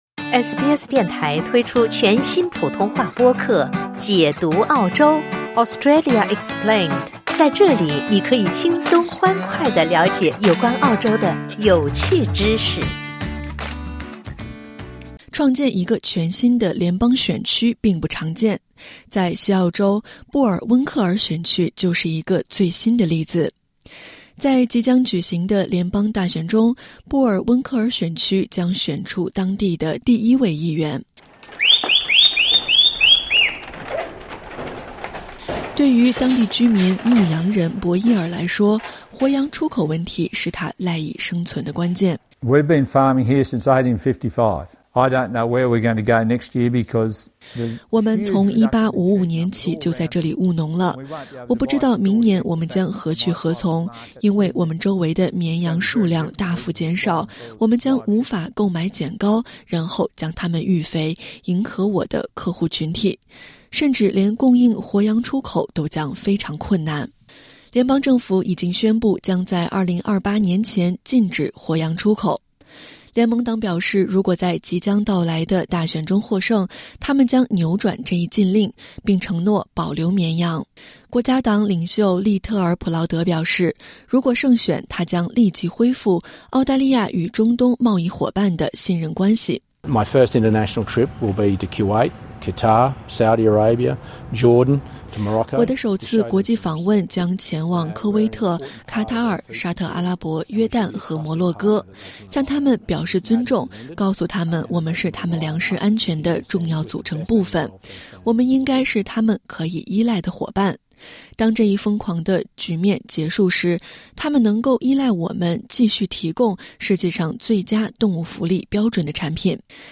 点击音频，收听报道。